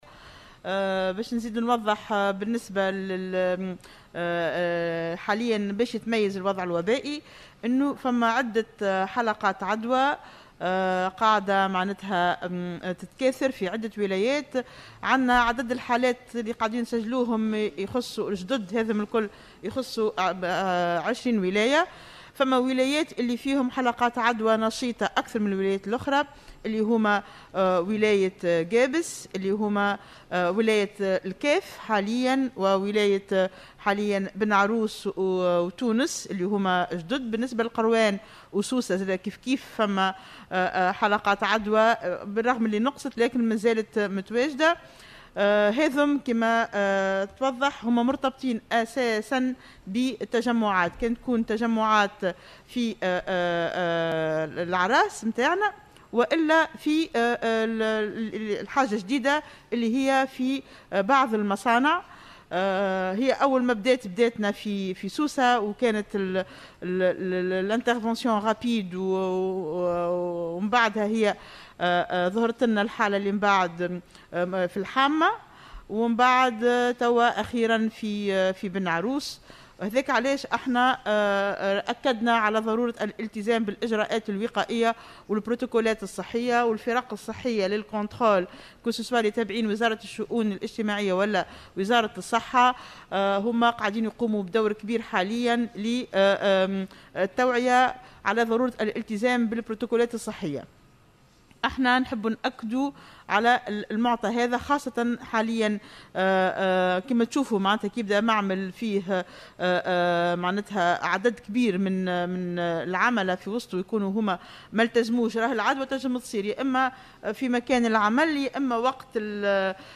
وأضافت بن علية خلال ندوة صحفية اليوم، أن هذه الإصابات مرتبطة أساسا بالتجمعات والأعراس وعدم التقيد بإجراءات الوقاية داخل المصانع (ارتداء الكمامات والتباعد الجسدي....).وبالنسبة لولايتي القيروان وسوسة، أكدت بن علية أن حلقات العدوى مازالت موجودة لكنها تراجعت قليلا، وفق قولها.